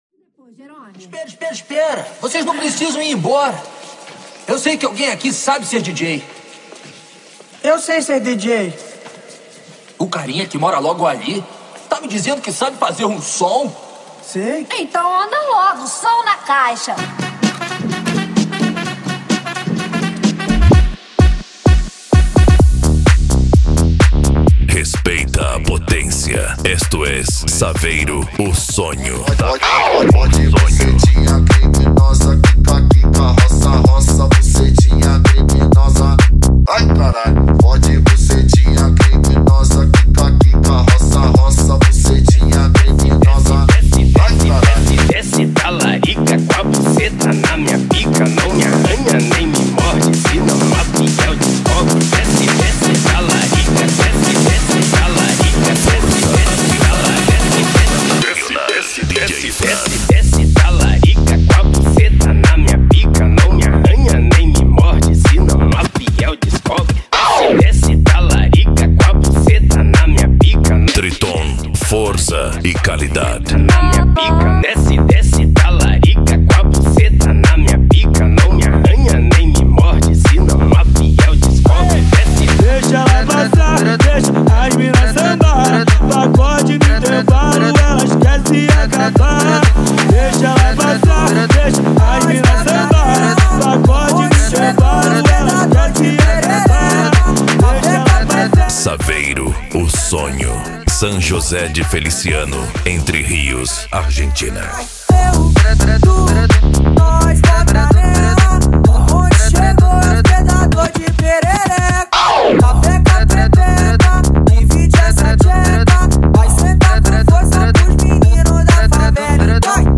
Bass
Funk
SERTANEJO